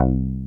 WOOD BASS 3.wav